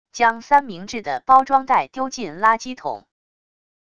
将三明治的包装袋丢进垃圾桶wav音频